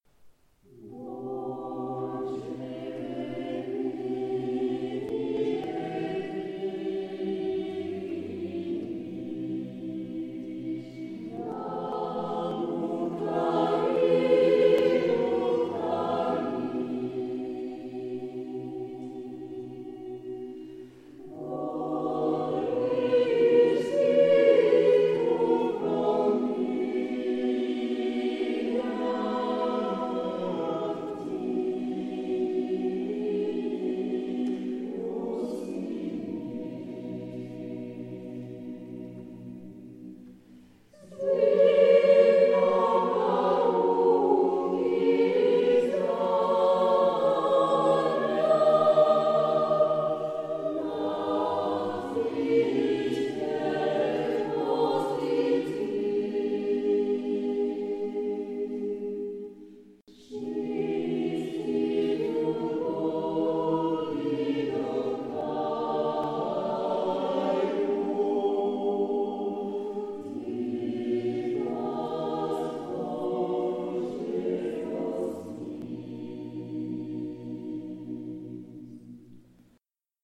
Musique chorale des pays de l'Est
Extraits de la restitution de fin de stage
dans l'église de Saint-Hugues de Chartreuse, Musée Arcabas, le 4 juillet 2023